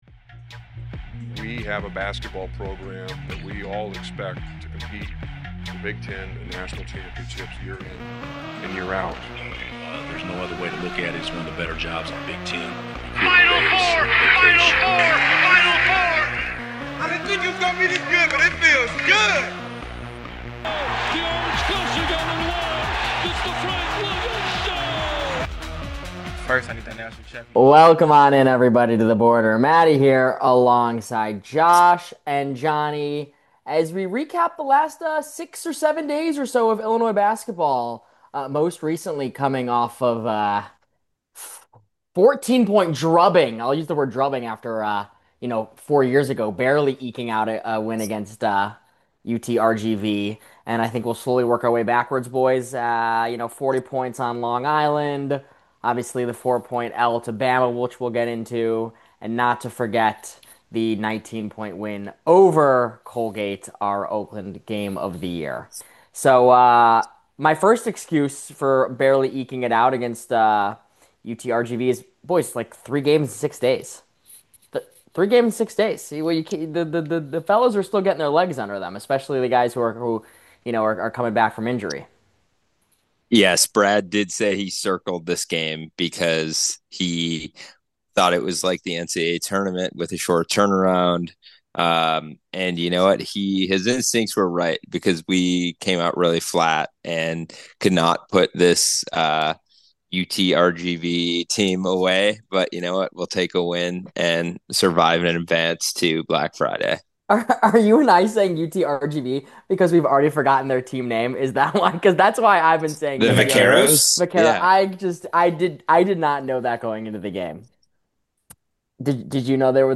The fellas get together to chat about the last four games and look ahead to the UConn-Tennessee gauntlet.